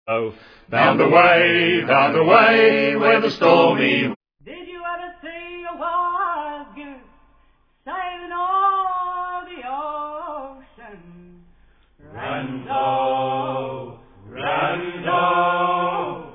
in front of a select audience